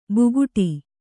♪ buguṭi